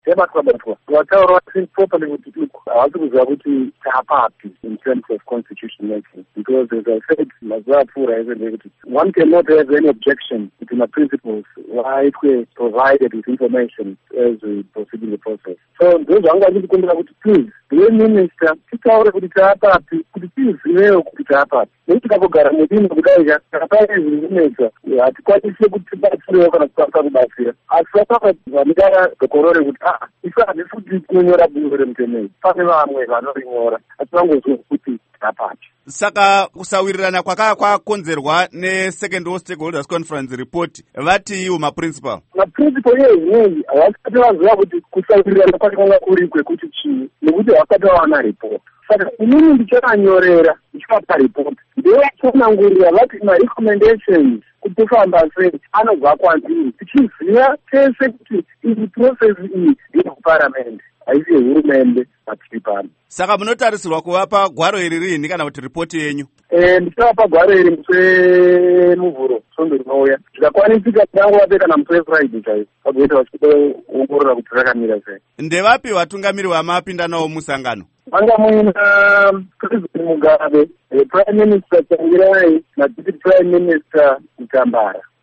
Hurukuro naVaEric Matinenga